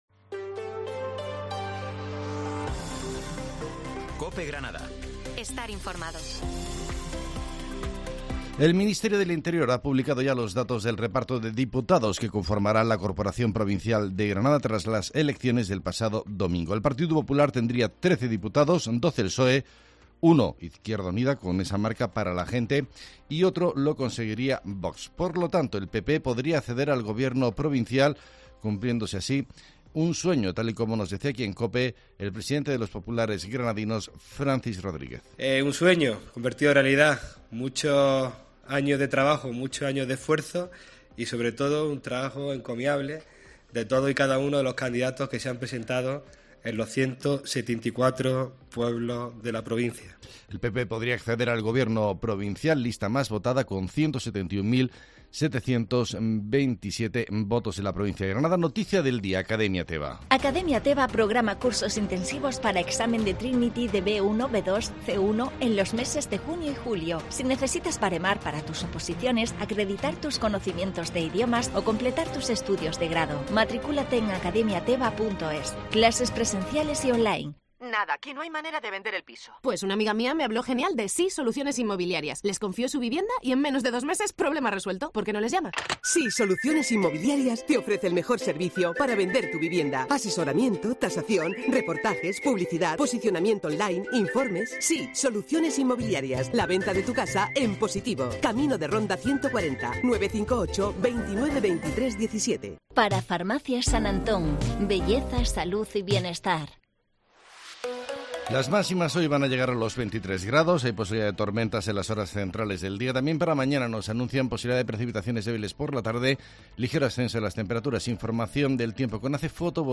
Herrera en Cope Granada, Informativo del 30 de mayo